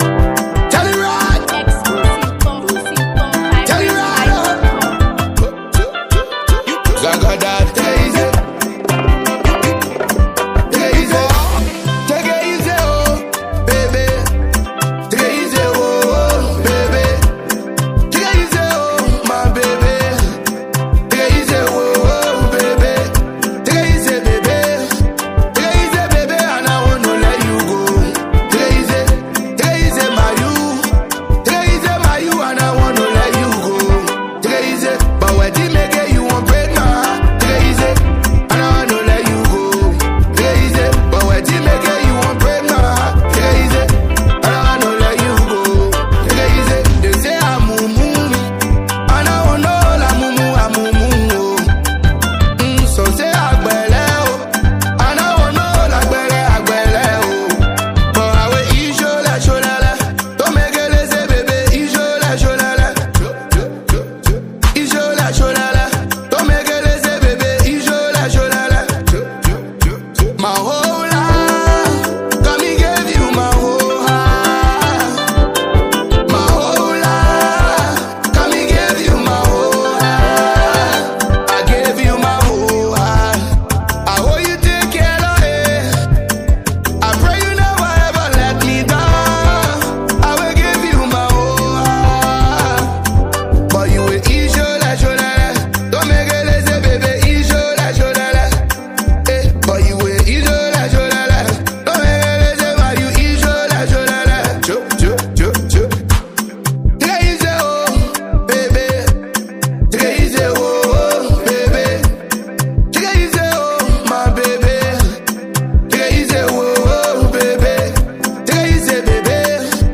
Liberia’s afrobeat trailblazer
Blending smooth vocals, rhythmic beats, and real-life lyrics
With a mature tone and relatable storytelling